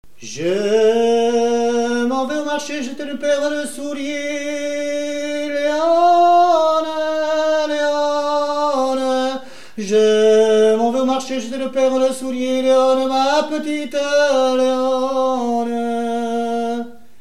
Genre dialogue
Pièce musicale inédite